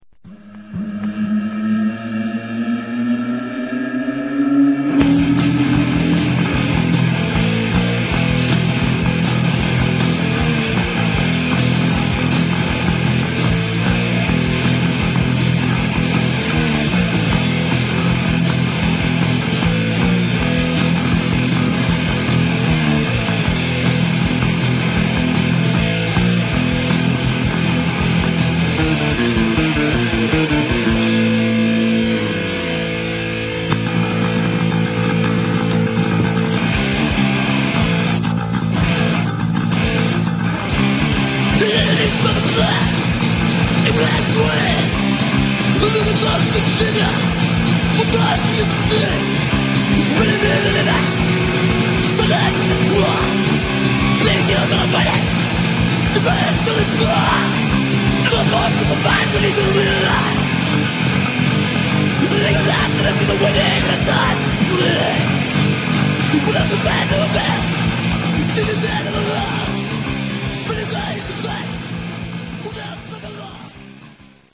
Recorded in June, 1986 at Studio 525, New Westminster, B.C.